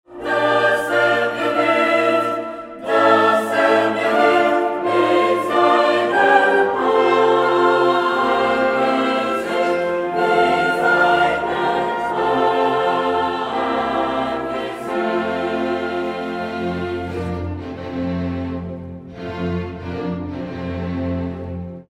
Extraits du concert
Mendelssohn – Psaume 42 (Chœur : Was betrübst du dich, meine Seele) /wp-content/uploads/2023/02/Mendelssohn-Psaume42Choeur.mp3
Mendelssohn-Psaume42Choeur.mp3